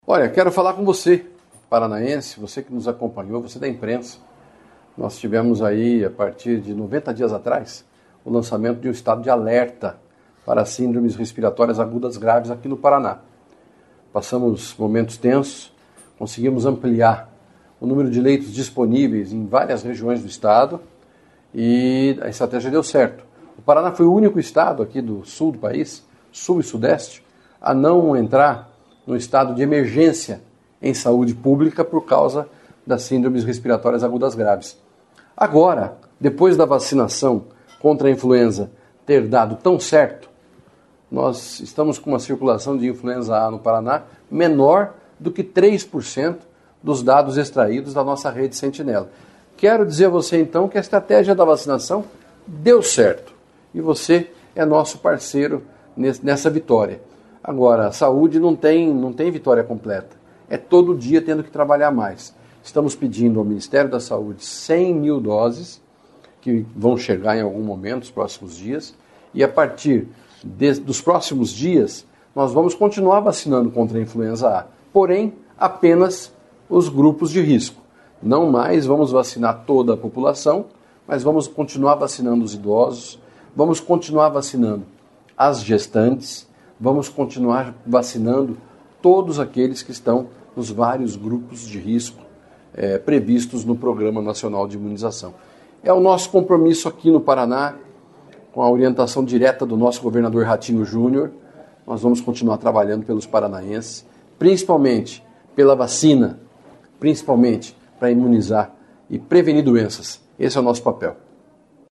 Sonora do secretário Estadual da Saúde, Beto Preto, sobre o fim do estado de alerta para SRAGs no Paraná